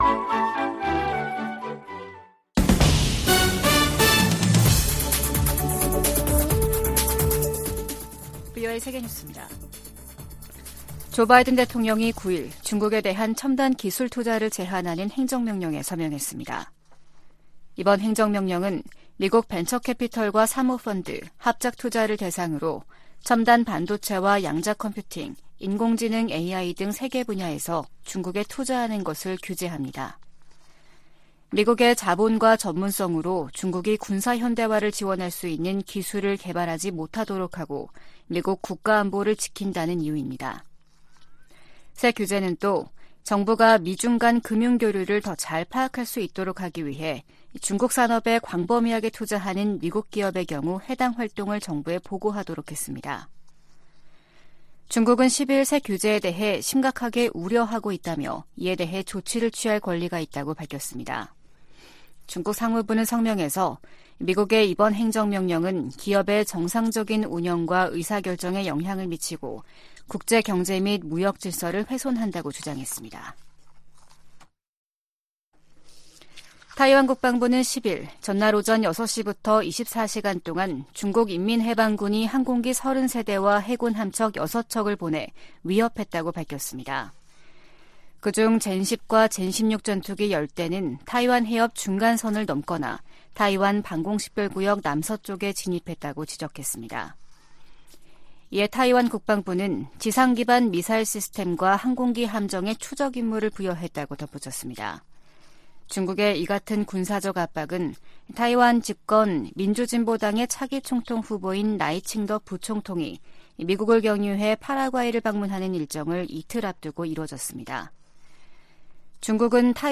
VOA 한국어 아침 뉴스 프로그램 '워싱턴 뉴스 광장' 2023년 8월 11일 방송입니다. 조 바이든 미국 대통령이 다음 주 열리는 미한일 정상회의에서 역사적인 논의를 고대하고 있다고 백악관 고위관리가 밝혔습니다. 미 국무부는 북한의 개성공단 무단 가동 정황과 관련해 기존 제재를 계속 이행할 것이라고 밝혔습니다. 김정은 북한 국무위원장이 '을지프리덤실드' 미한 연합연습을 앞두고 노동당 중앙군사위원회 확대회의를 열어 '공세적 전쟁 준비'를 강조했습니다.